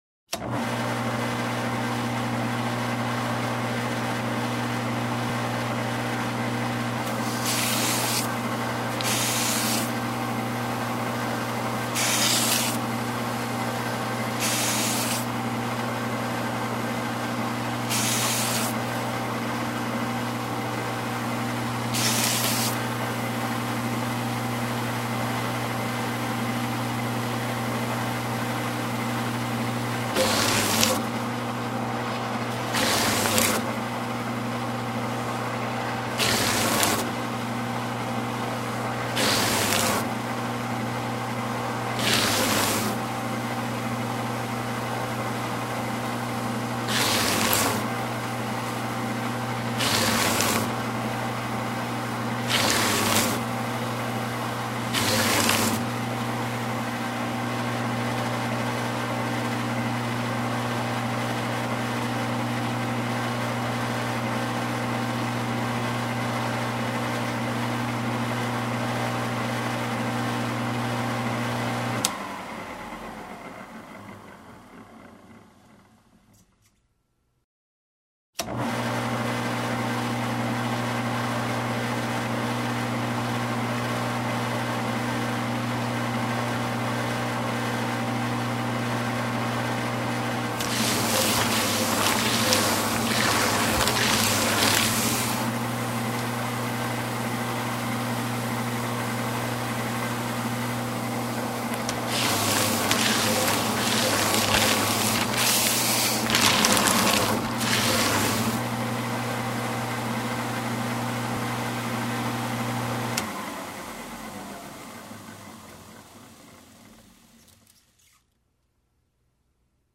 На этой странице собраны звуки работы шредера — от плавного жужжания до резкого измельчения бумаги.
Шуршание шредера: звук измельчения бумаги